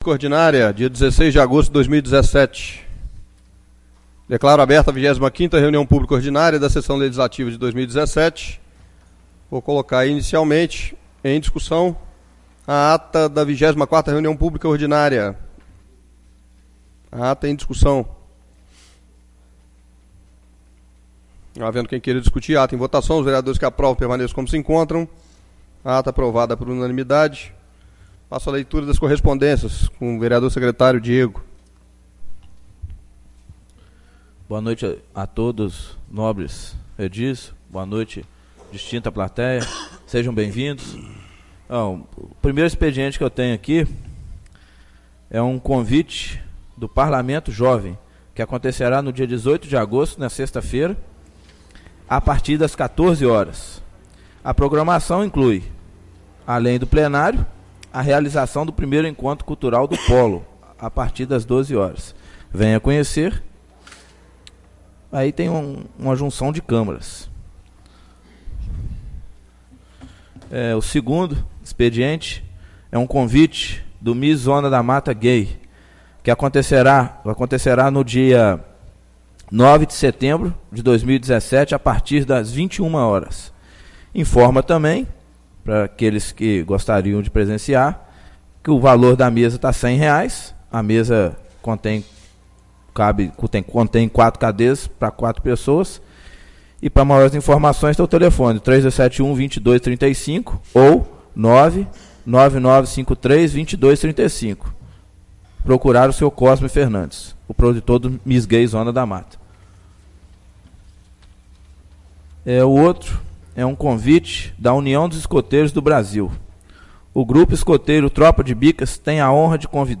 25° Reunião Publica Ordinária 16/08/2017